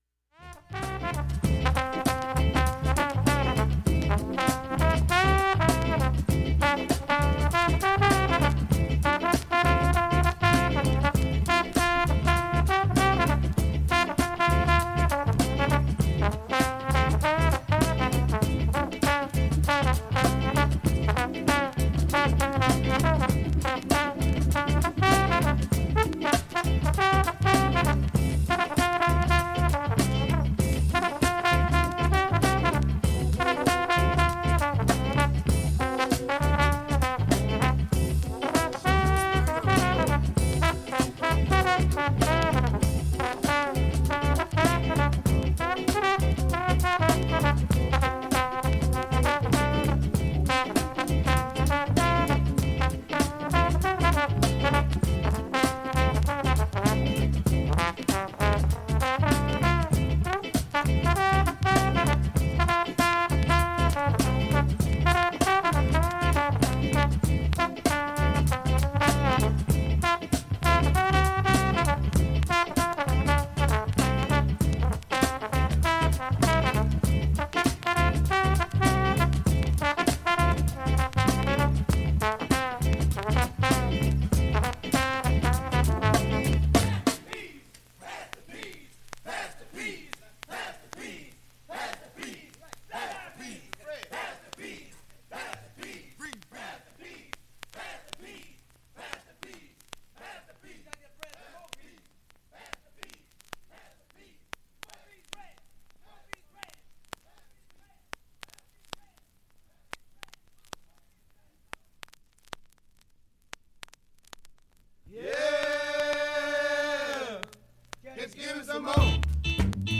きれいないい音質です。
A-4終わりフェイドアウト部に
小さい点キズでわずかなプツ5回出ます。
現物の試聴（上記録音時間4分）できます。音質目安にどうぞ